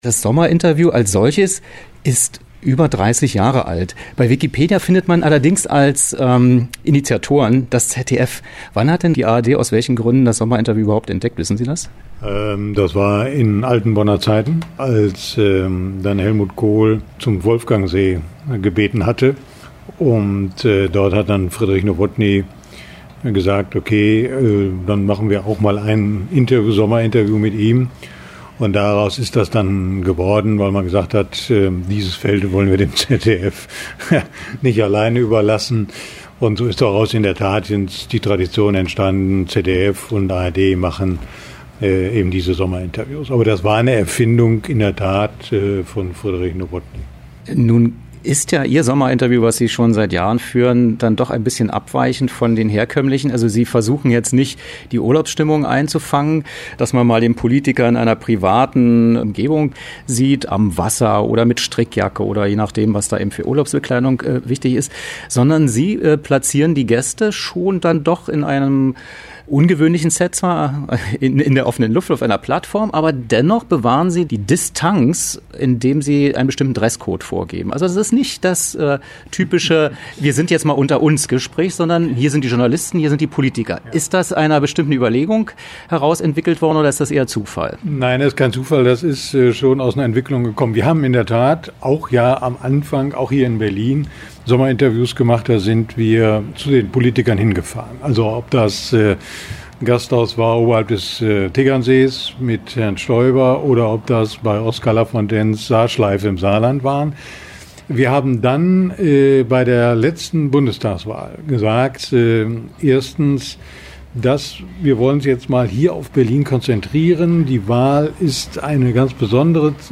Sommerinterview
Wo: Berlin, ARD-Hauptstadtstudio